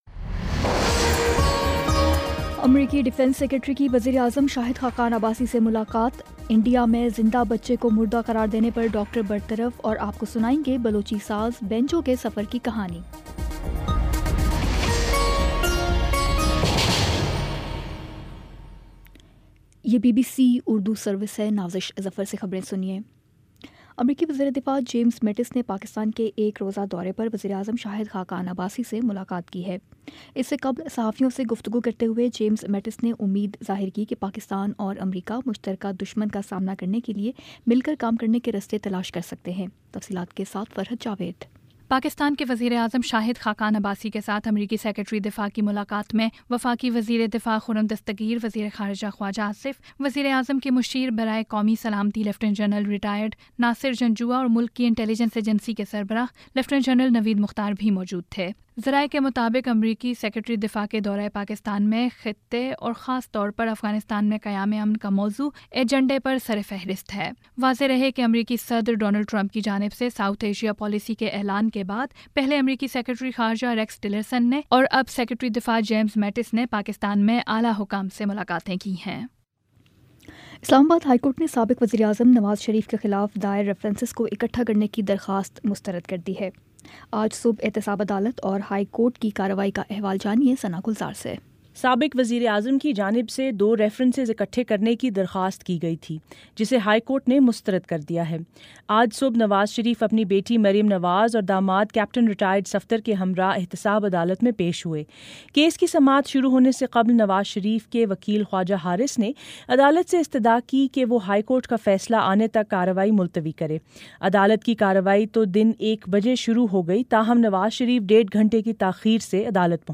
دسمبر 04 : شام چھ بجے کا نیوز بُلیٹن